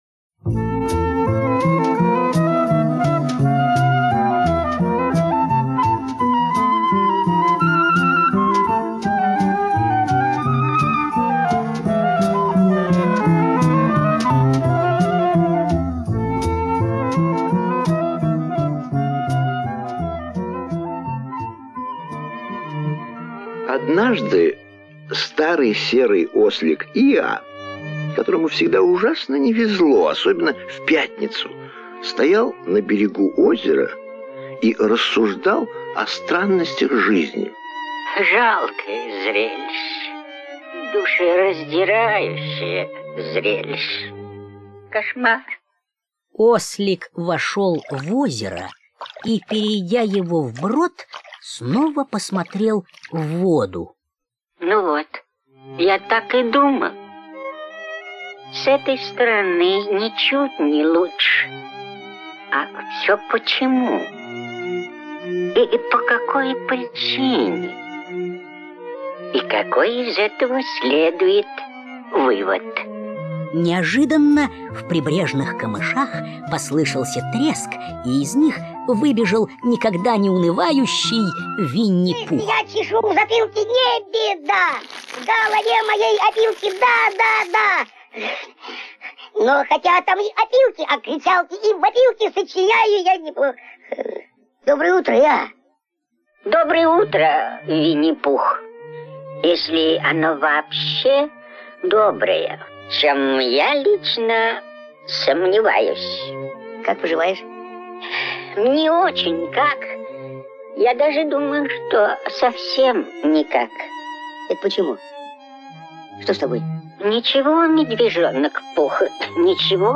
Винни-Пух и день забот - аудиосказка Милн - слушать онлайн